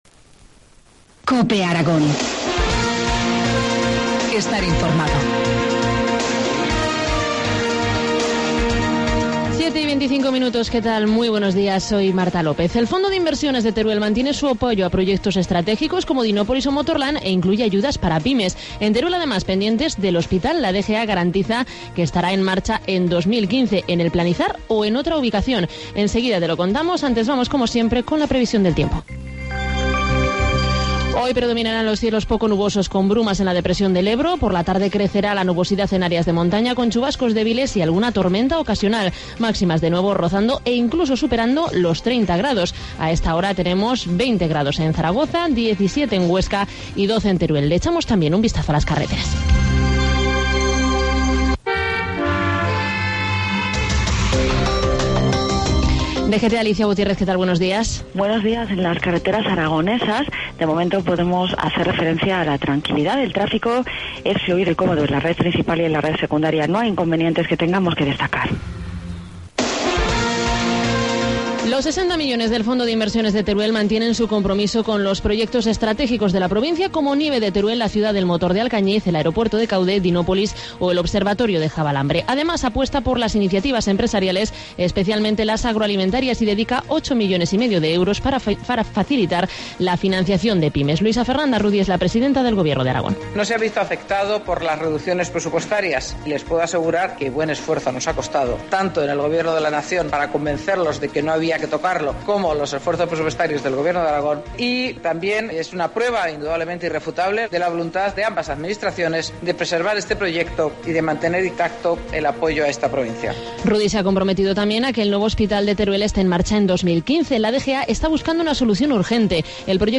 Informativo matinal, miercoles 25 septiembre, 2013, 7,25 horas